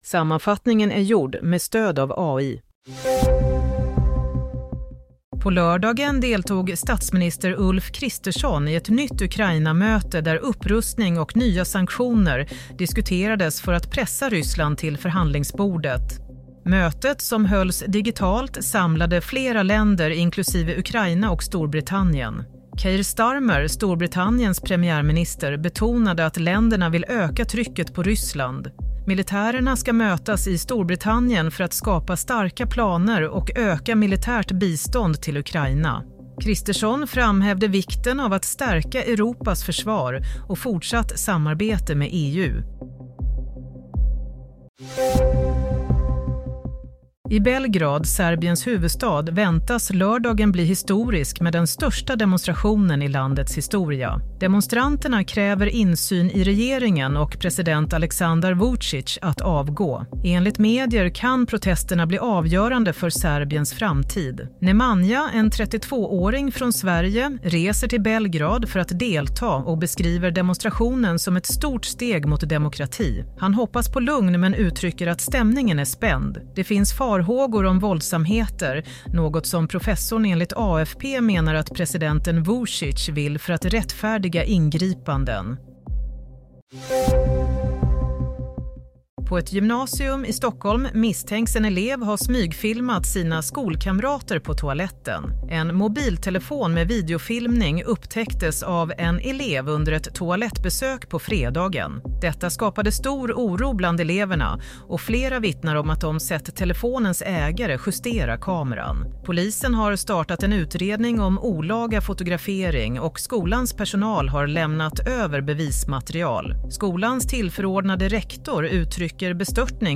Nyhetssammanfattning - 15 mars 16:00